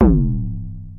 描述：FM sweeper.
标签： fm synth bass modular
声道立体声